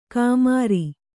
♪ kāmāri